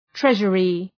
{‘treʒərı}